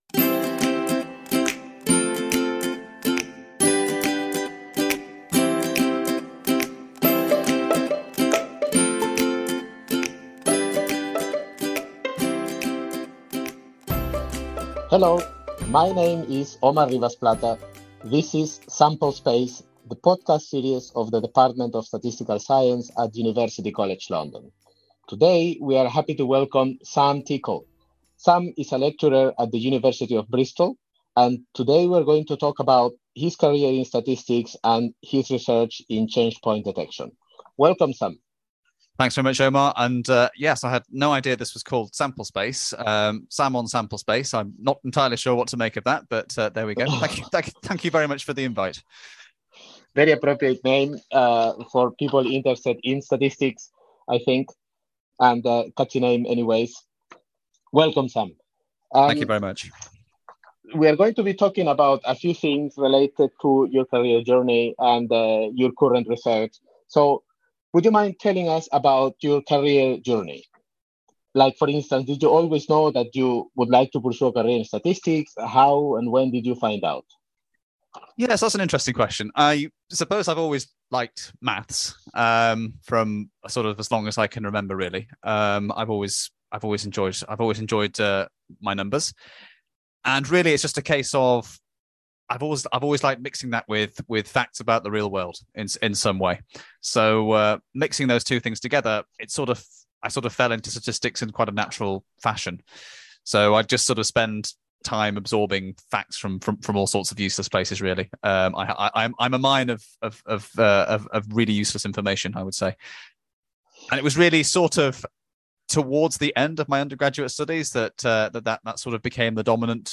In this interview from the Department of Statistical Science at UCL